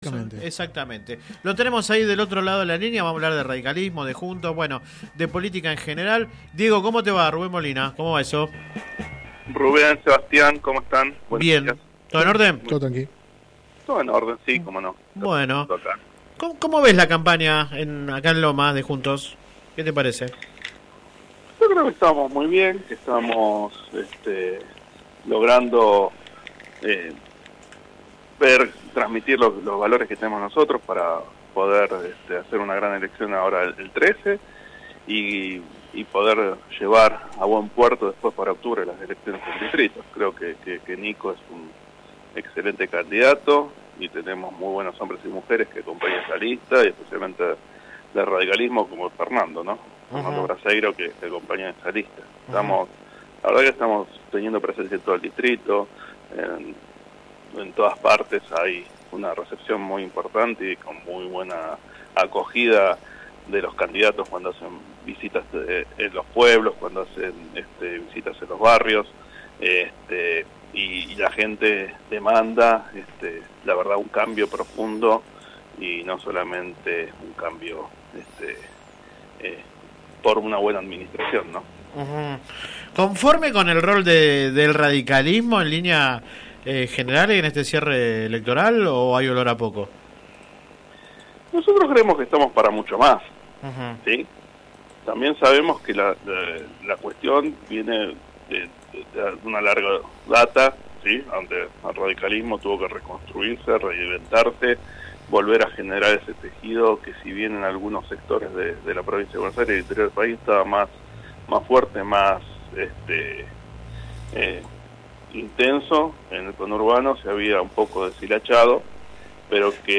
En contacto con el programa radial Sin Retorno (lunes a viernes de 10 a 13 por GPS El Camino FM 90 .7 y AM 1260) habló de su partido, el futuro y las elecciones que se avecinan.
Click acá entrevista radial https